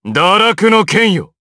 DarkKasel-Vox_Skill3_jp.wav